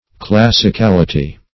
Search Result for " classicality" : The Collaborative International Dictionary of English v.0.48: Classicality \Clas`si*cal"i*ty\, Classicalness \Clas"sic*al*ness\, n. The quality of being classical.